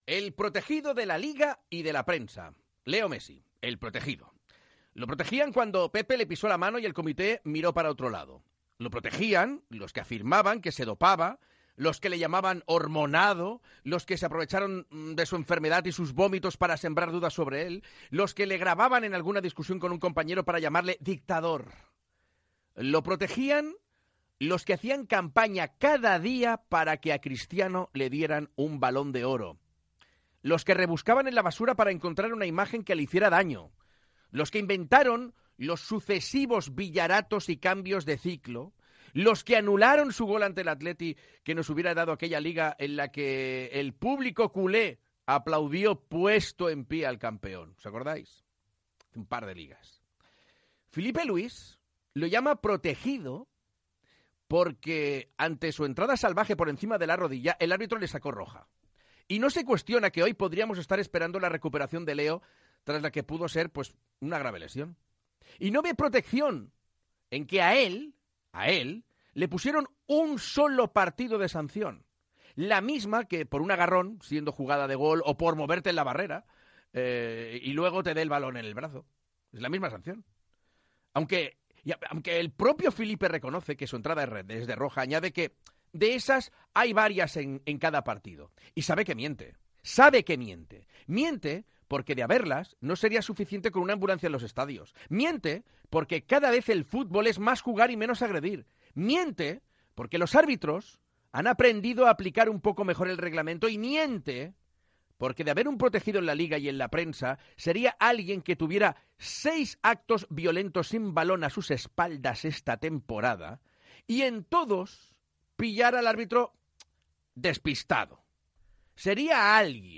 Comentari sobre el jugador Leo Messi, careta del programa, sumari, indicatiu, notícies futbolístiques, indicatiu, publicitat, notícies curioses, indicatiu Gènere radiofònic Esportiu